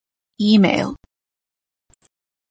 /ˈimeil/